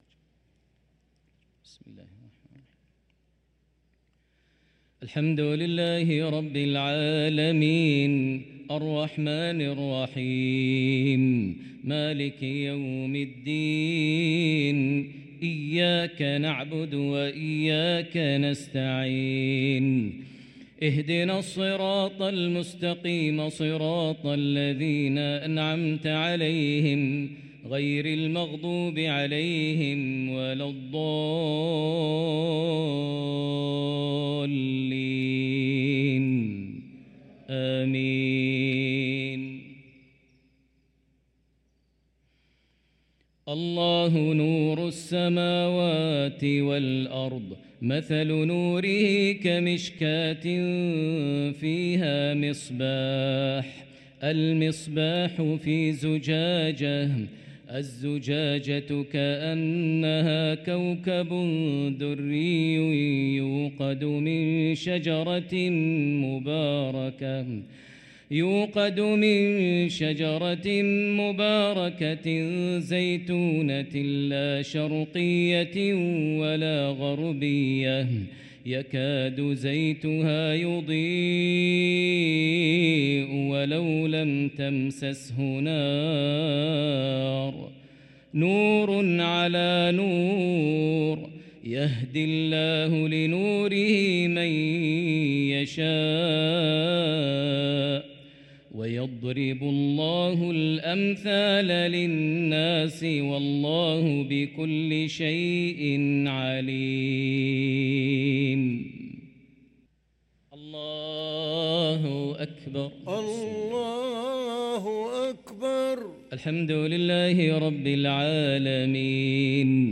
صلاة المغرب للقارئ ماهر المعيقلي 18 ربيع الأول 1445 هـ